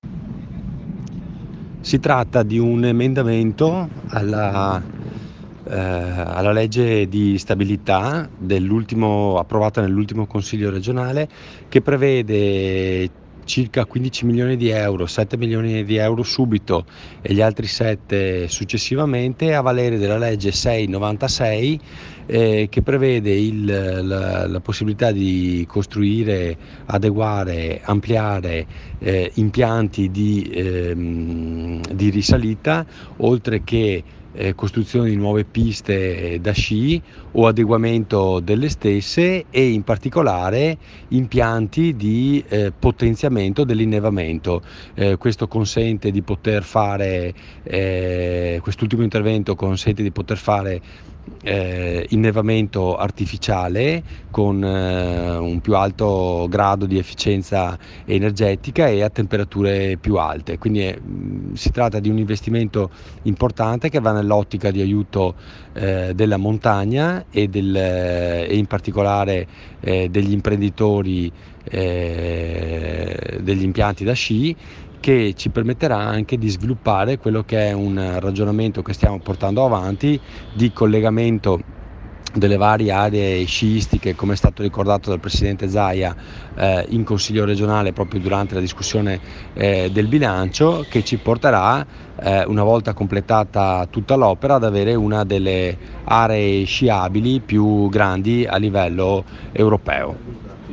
GIORNALE RADIOPIU 07 DICEMBRE